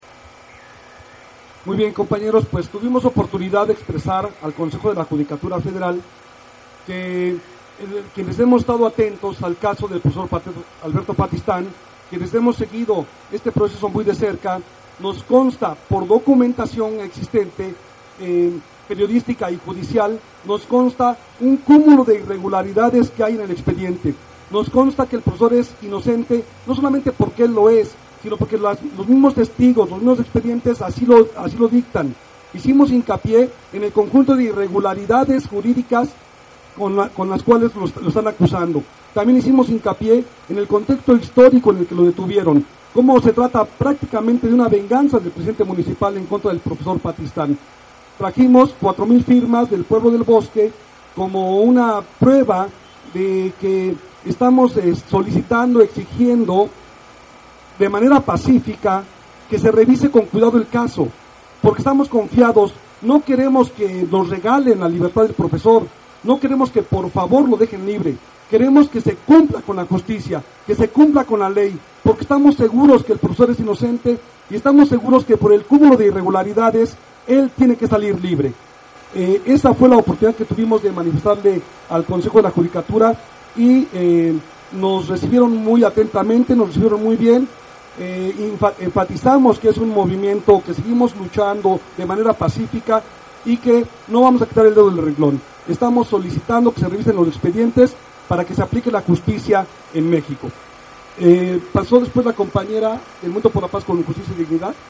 Desde muy temprana hora se congregaron aproximadamente 300 personas entre ellas compañerxs de la Sexta, organizaciones como la Coordinadora Nacional Plan de Ayala, medios libres, colectivos libertarios, entre otros.
Palabras de la comisión a su salida del Consejo de la Judicatura Federal